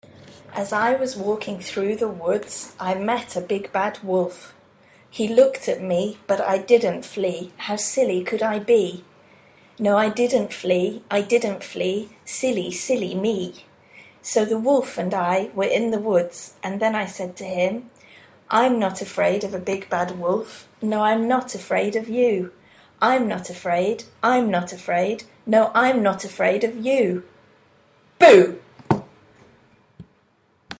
I'm not afraid of the big bad wolf (fast)